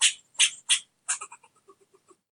sounds_squirrel_02.ogg